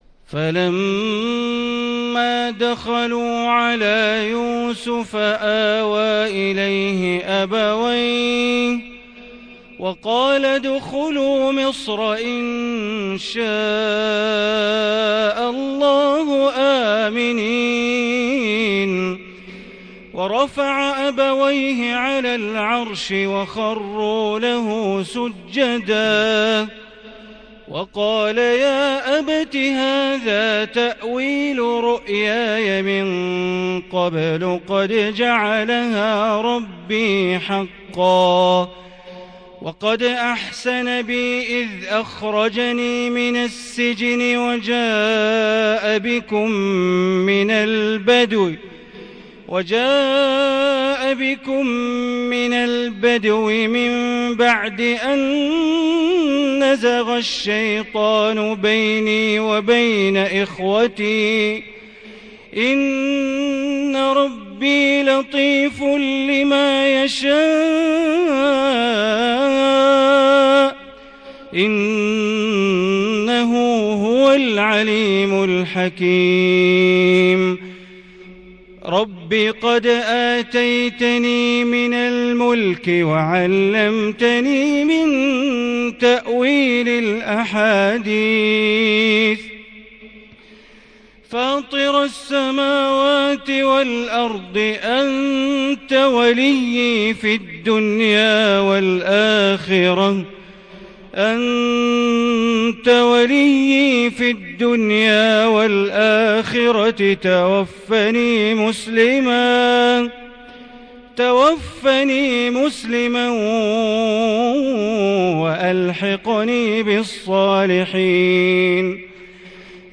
عشاء 4 شعبان 1440هـ من سورة يوسف | Sunday AL-isha prayer , From surah Yusuf 9- 4- 2019 > 1440 🕋 > الفروض - تلاوات الحرمين